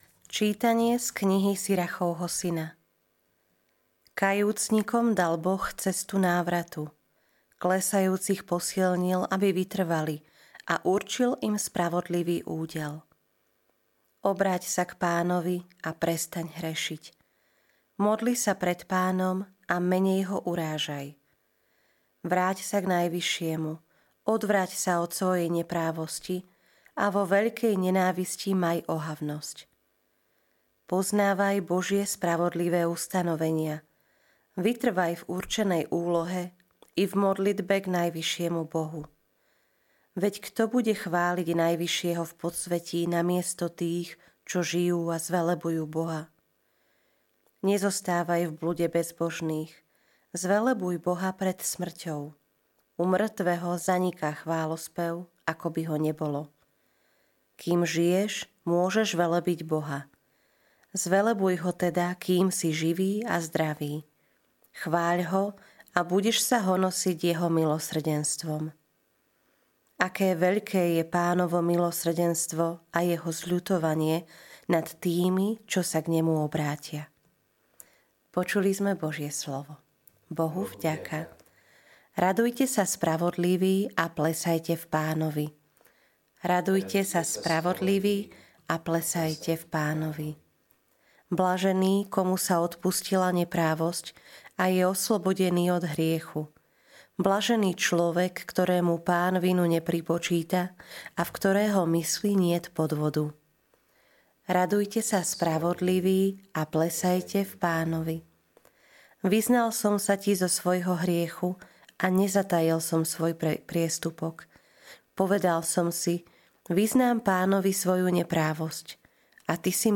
LITURGICKÉ ČÍTANIA | 3. marca 2025